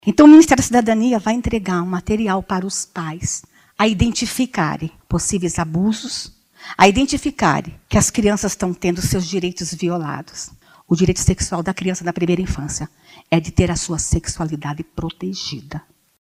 A iniciativa é voltada a conscientizar a população sobre os riscos e as consequências de uma gestação precoce e prevê a capacitação de diferentes públicos para lidar com o tema, bem como combater a violência. Como explica a ministra Damares Alves.